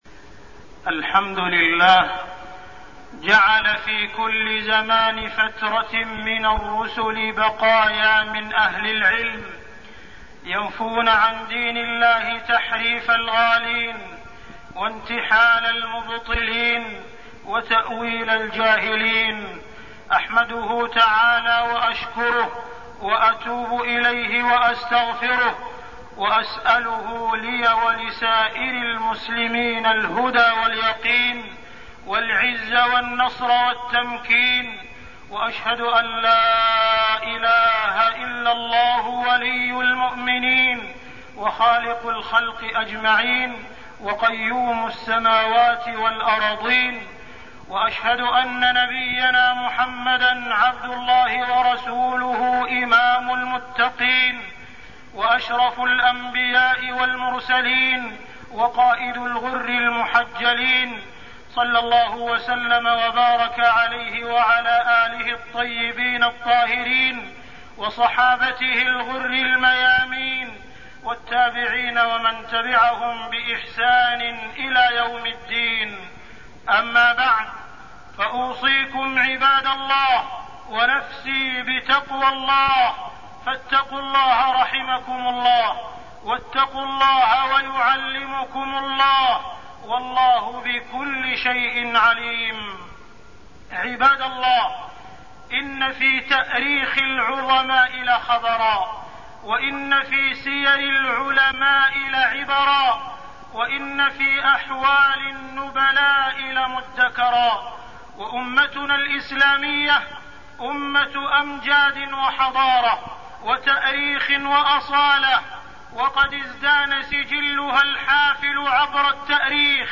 تاريخ النشر ١٩ جمادى الأولى ١٤١٦ هـ المكان: المسجد الحرام الشيخ: معالي الشيخ أ.د. عبدالرحمن بن عبدالعزيز السديس معالي الشيخ أ.د. عبدالرحمن بن عبدالعزيز السديس العلماء العاملون